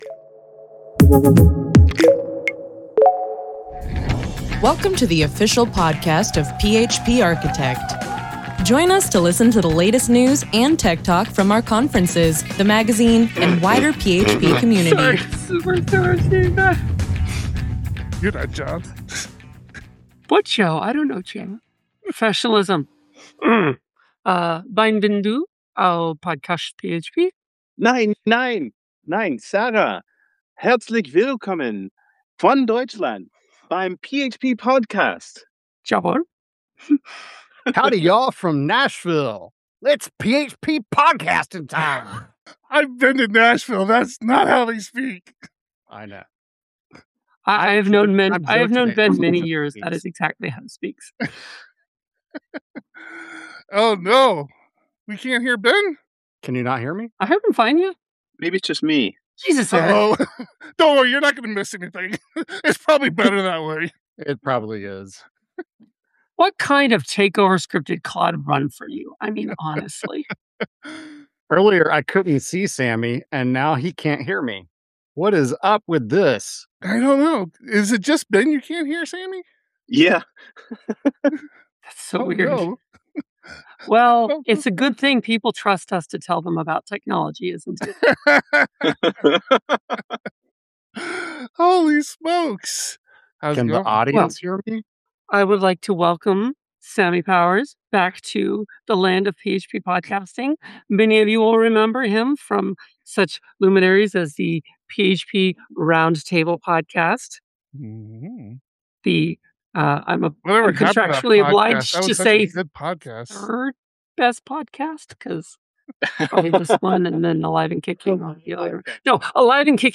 The PHP Podcast streams live, typically every Thursday at 3 PM PT.